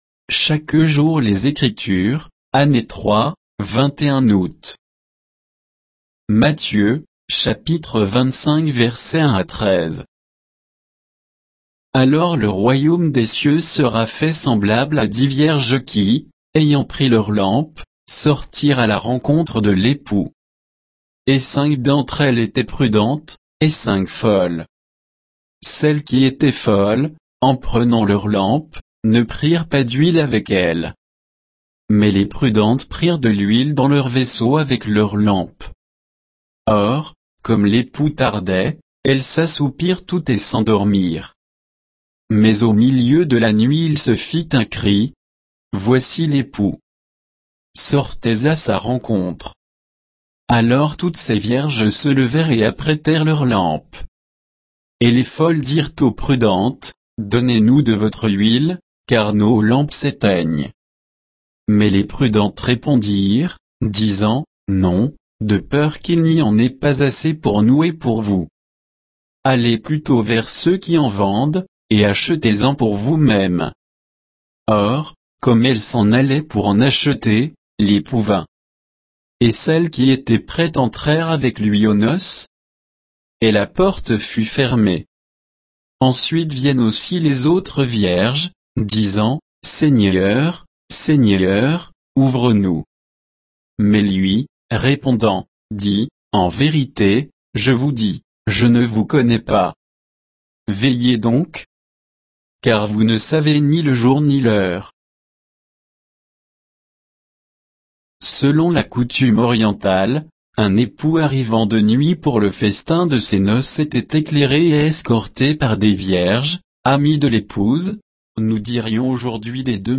Méditation quoditienne de Chaque jour les Écritures sur Matthieu 25